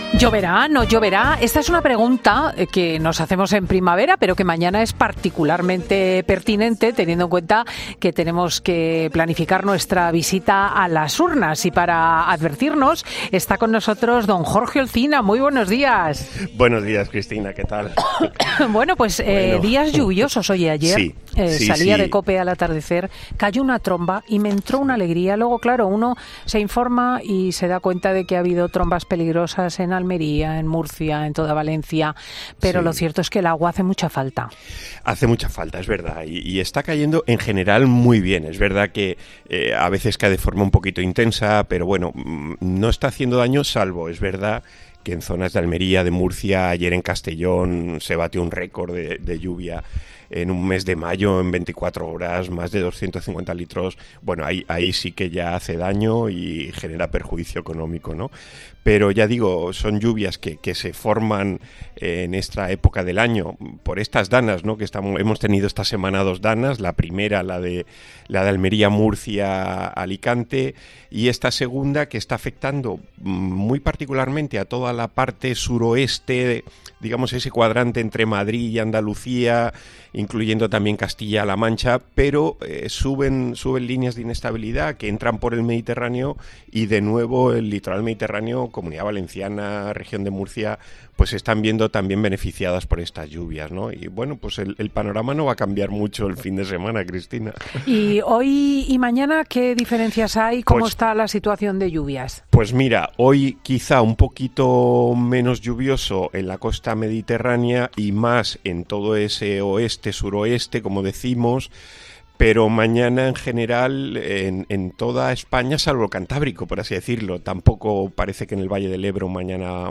¿Lloverá durante todo el 28-M? Un meteorólogo explica cuál es el mejor momento para ir a votar: "Atento"